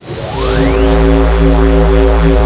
PhazonGun_0.wav